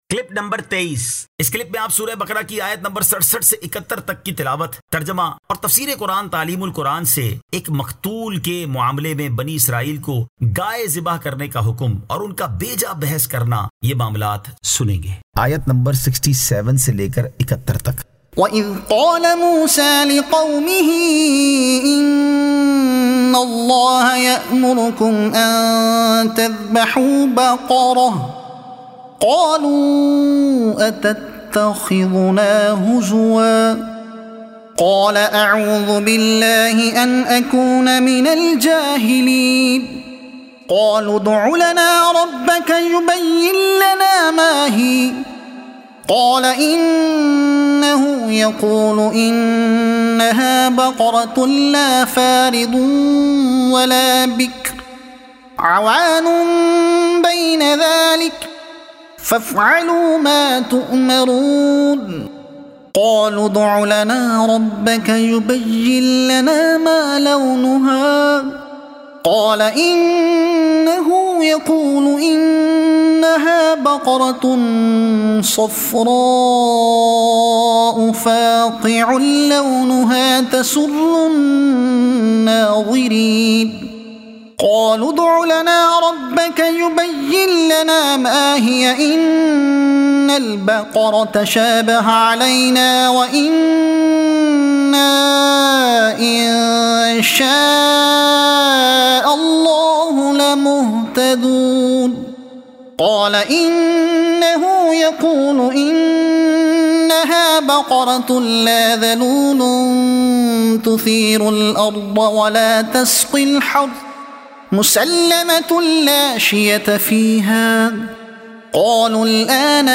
Surah Al-Baqara Ayat 67 To 71 Tilawat , Tarjuma , Tafseer e Taleem ul Quran